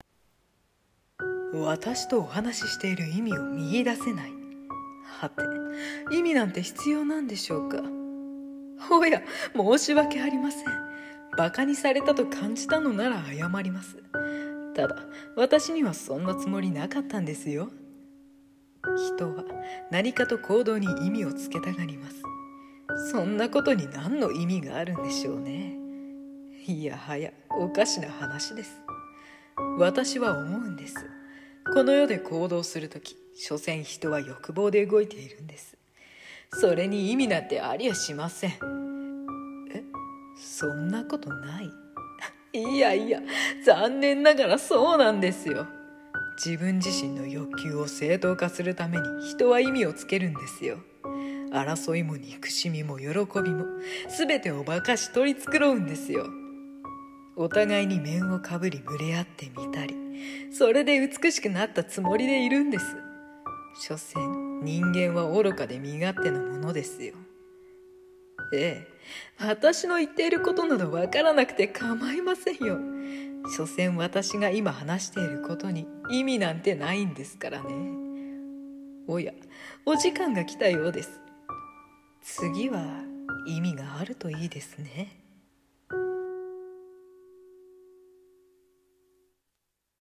【声劇】所詮そんなモノです。